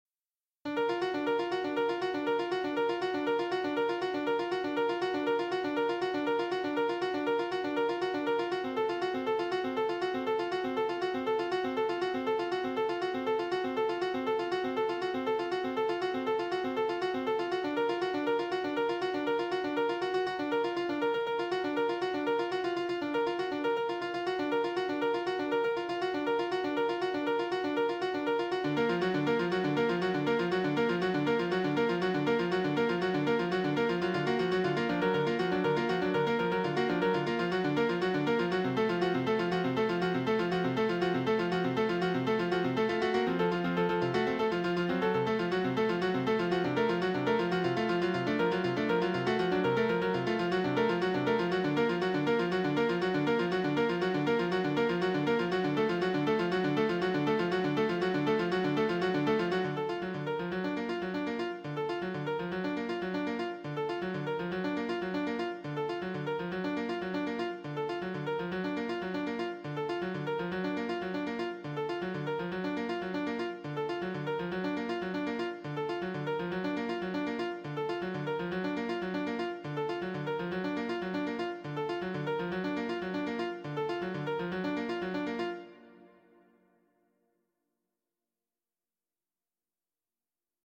mp3 (computer generated)